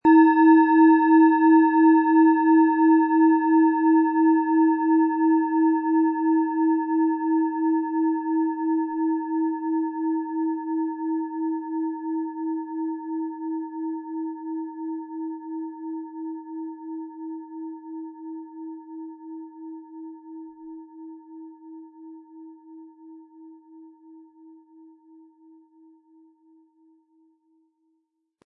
Planetenschale® Entspannt sein & Gedankenruhe mit Alphawellen, Ø 11,5 cm, 180-260 Gramm inkl. Klöppel
Planetenton 1
Im Sound-Player - Jetzt reinhören können Sie den Original-Ton genau dieser Schale anhören.